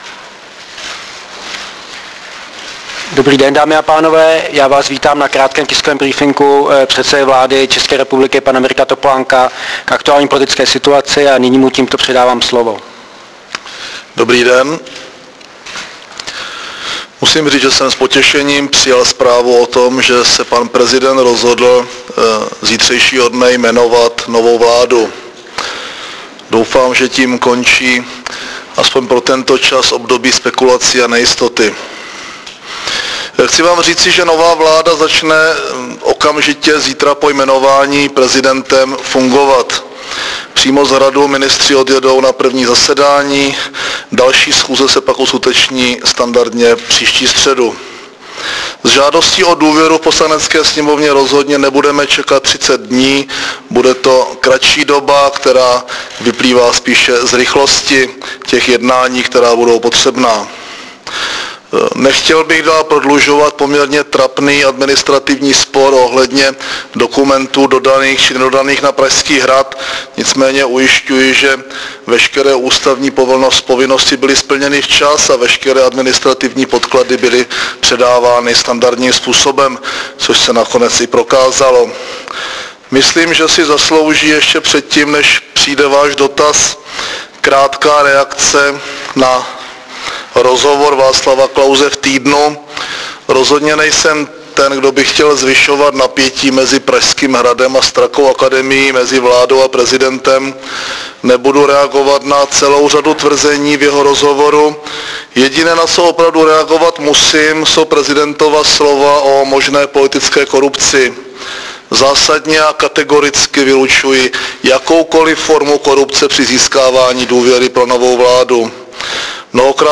Tiskový briefing předsedy vlády ČR Mirka Topolánka v pondělí 8. 1. 2007 k aktuální politické situaci
Zvukový záznam z tiskového briefingu